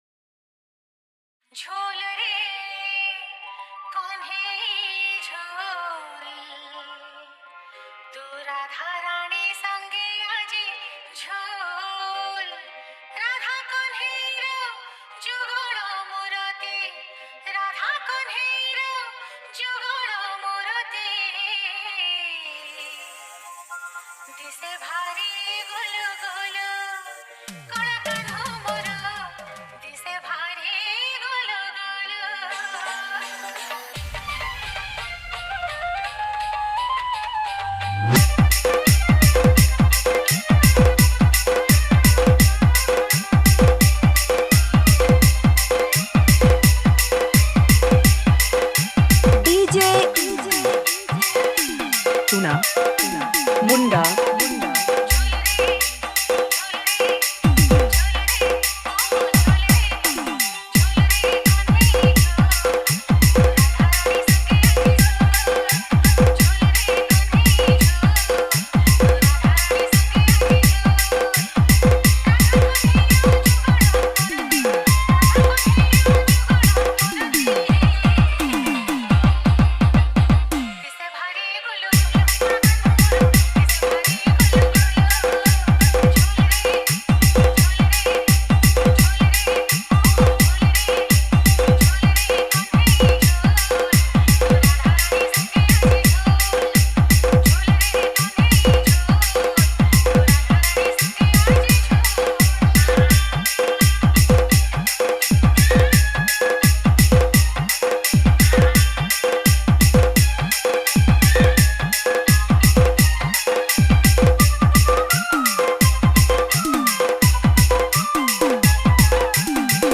Category:  Odia Bhajan Dj 2022